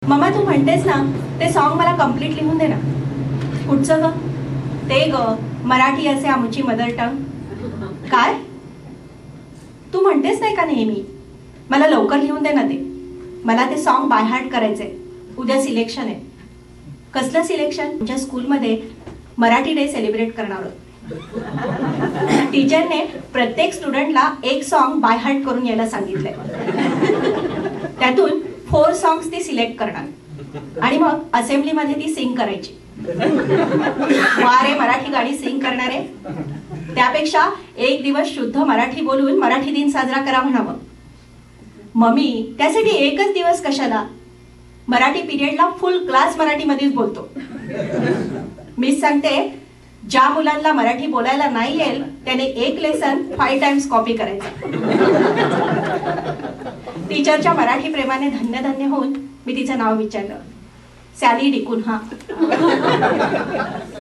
Sample from live performance in Orlando,FL in Feb 2006